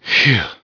phew.wav